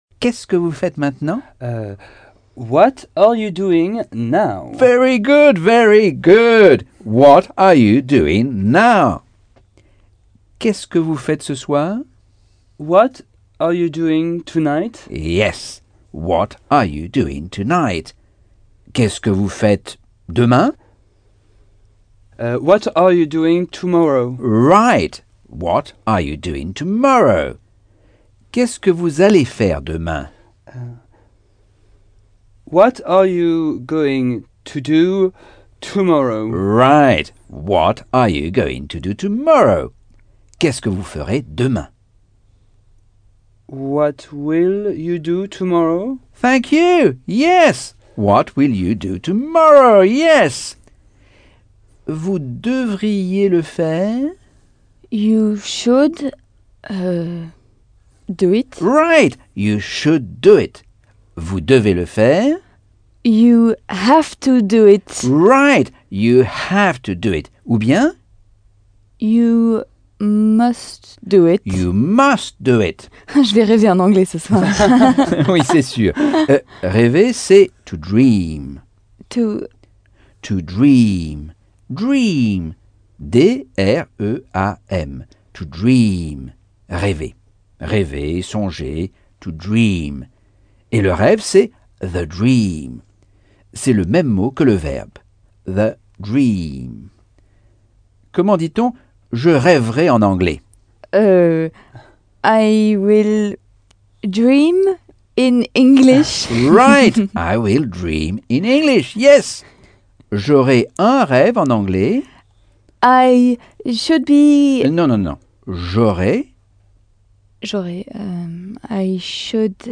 Leçon 8 - Cours audio Anglais par Michel Thomas